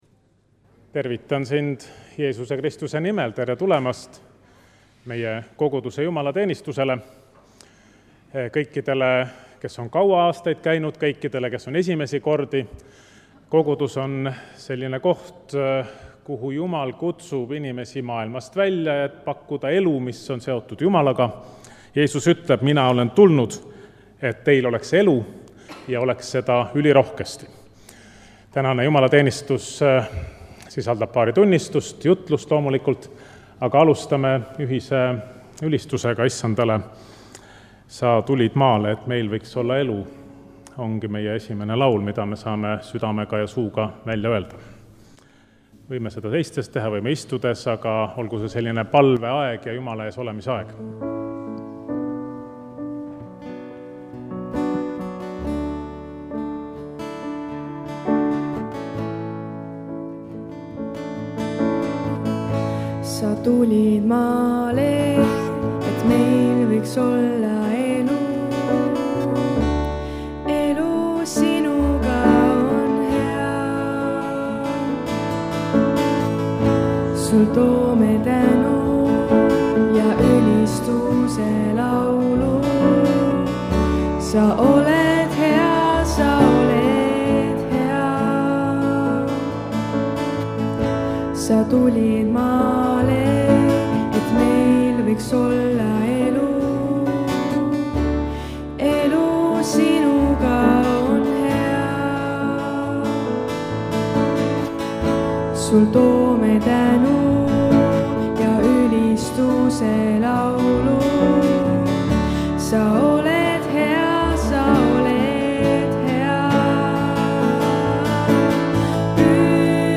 Muusika: Kolgata noortebänd